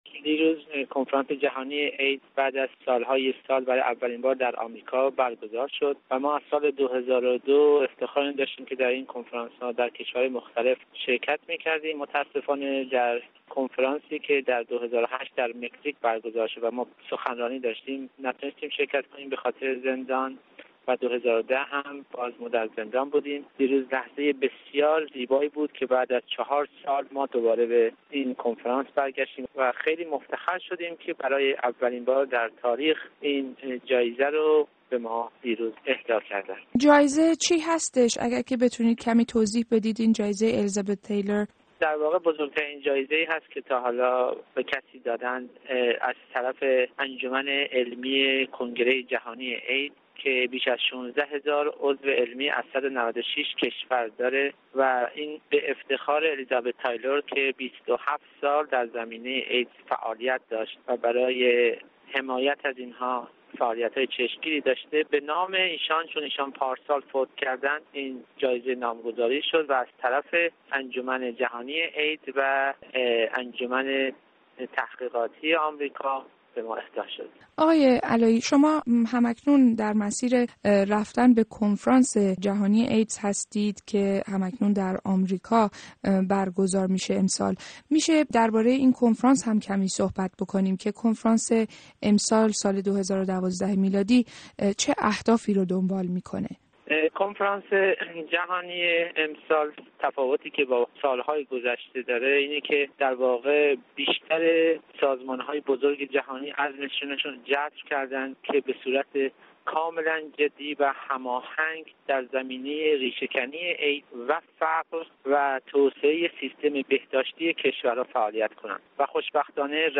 گفت و گوی رادیو فردا